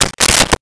Switchboard Telephone Receiver Put Down 03
Switchboard Telephone Receiver Put Down 03.wav